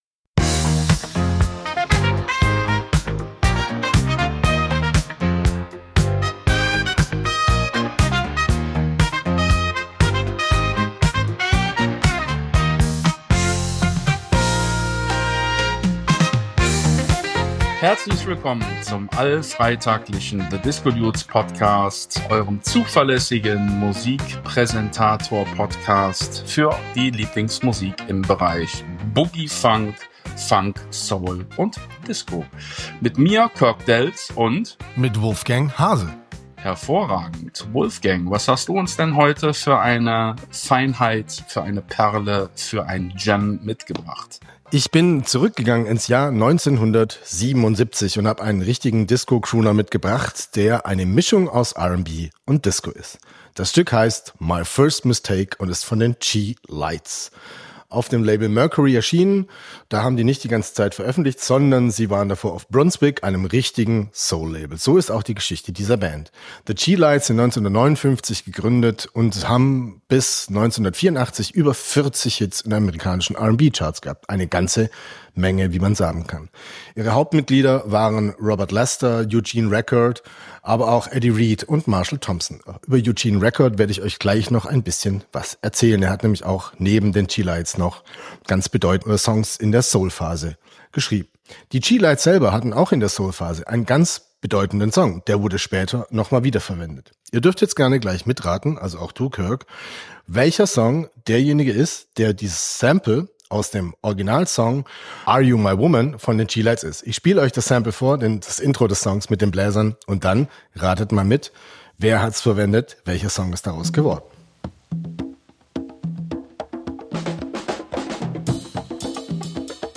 Diese Woche wird R&B von smooth & slow zu Disco und ein breakiger Disco-Kracher aus L.A. schickt uns auf den Floor.